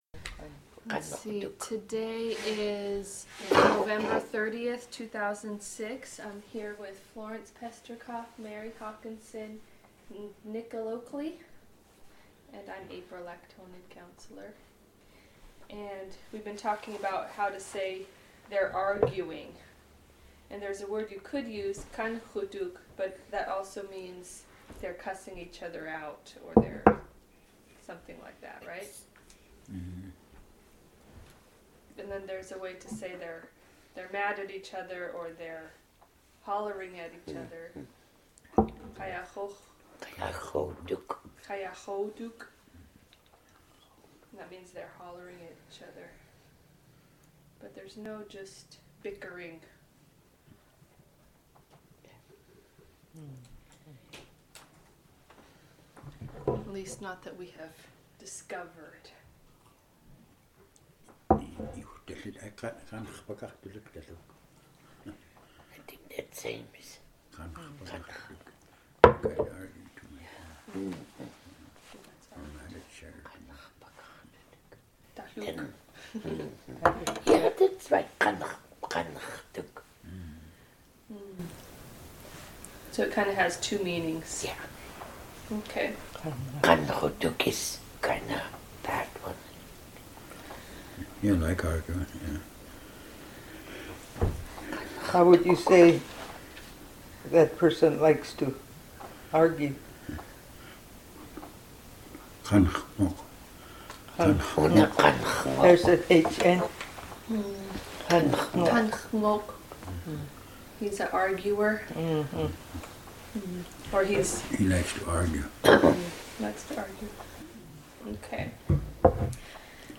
Access audio Summary: singing "kiak," buy and present, sentence Description: singing "kiak," buy and present, sentence Original Format: MiniDisc (AM470:301A) Migration: CD (AM470:301B) Location: Location Description: Kodiak, Alaska